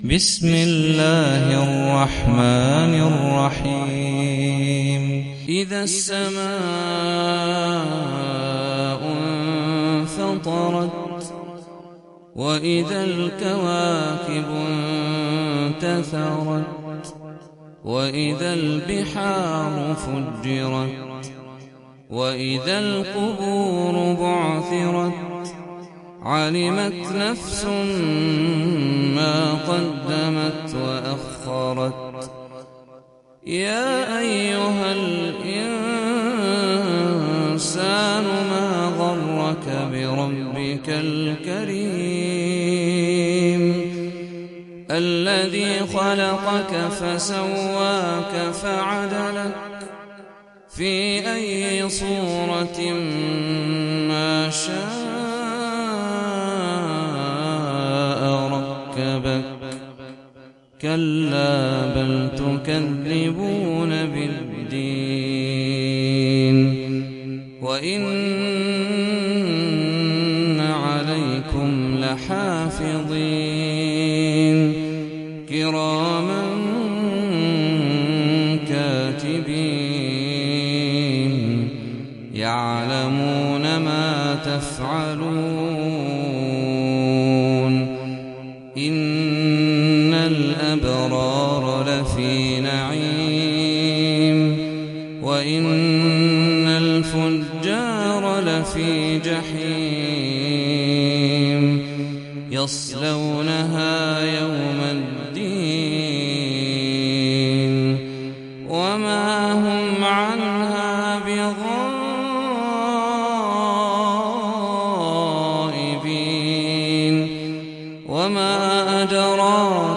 سورة الانفطار - صلاة التراويح 1446 هـ (برواية حفص عن عاصم)
جودة عالية